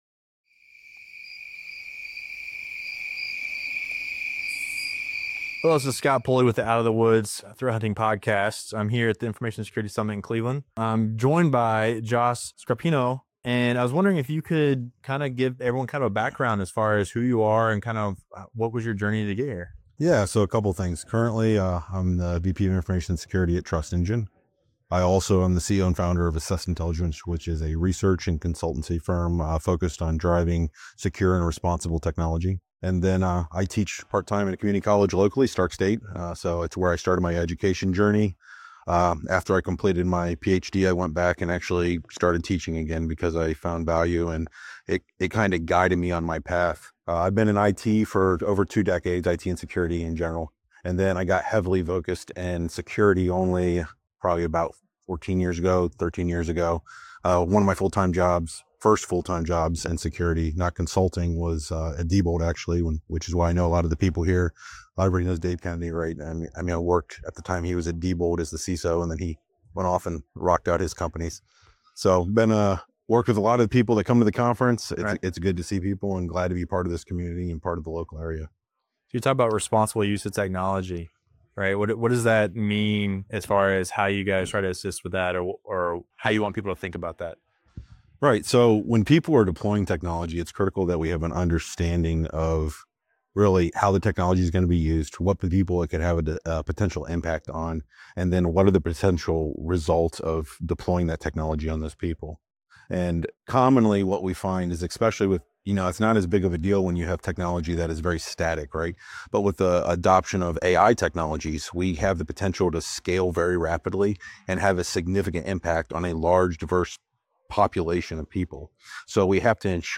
during the Information Security Summit in Cleveland.